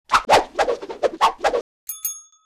Added audio of Pulver combining stuff